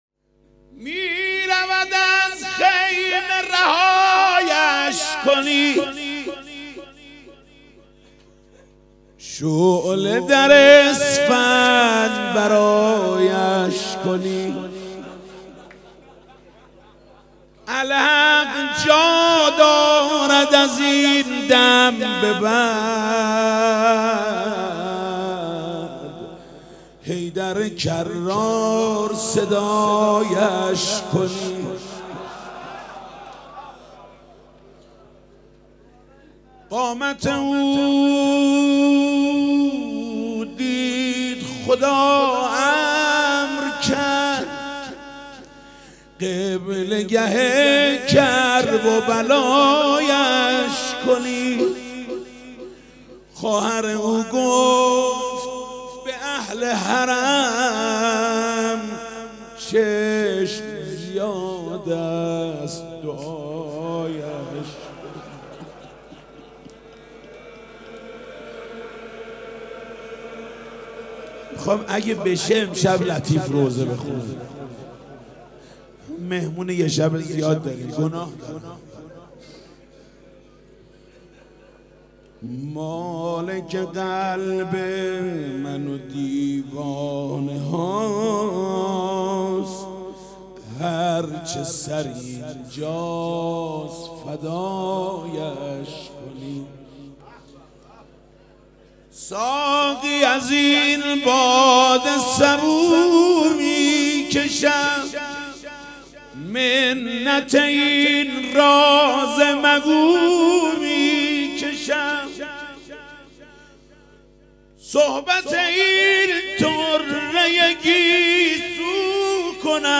هیأت رایه العباس(ع) رزمندگان شمیرانات
مداحی